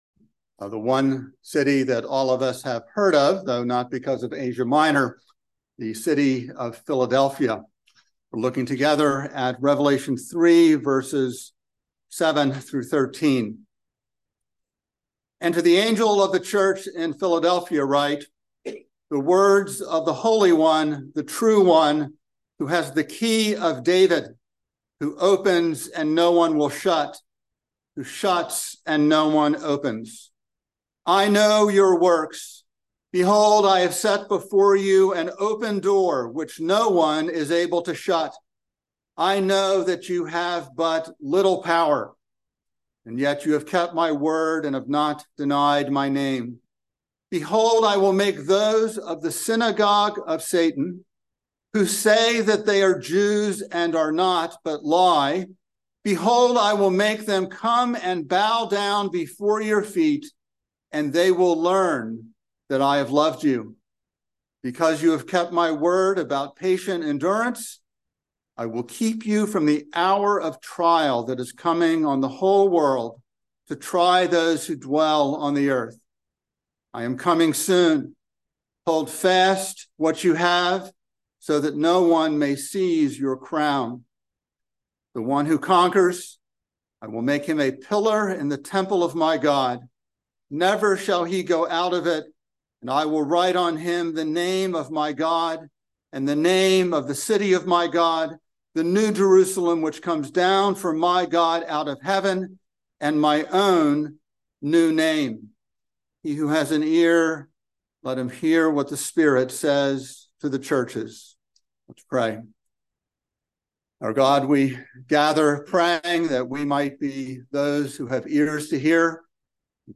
by Trinity Presbyterian Church | Nov 13, 2023 | Sermon